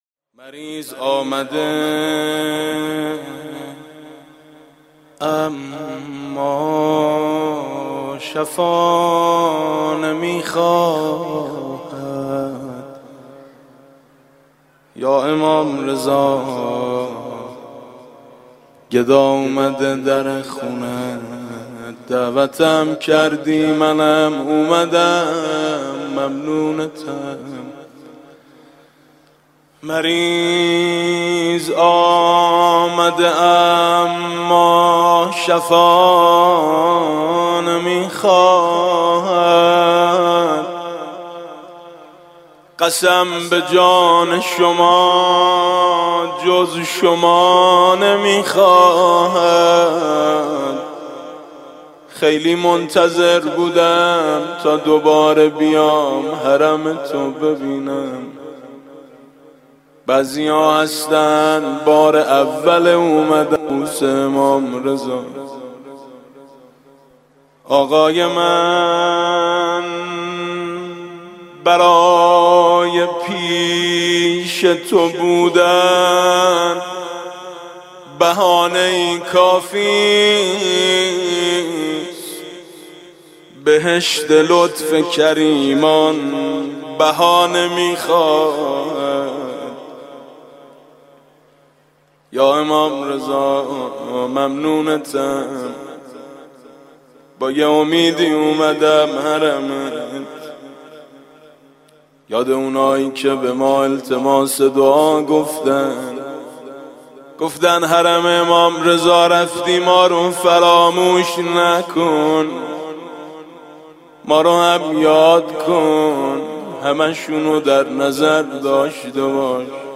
ضمن عرض تسلیت به هموطنان گرامی، به مناسبت فرا رسیدن سالروز شهادت امام رضا (ع) فایل صوتی روضه امام رضا (ع) با صدای میثم مطیعی در ادامه به همراه متن روضه، منتشر شده است.
meysam motiei - Emam Reza.mp3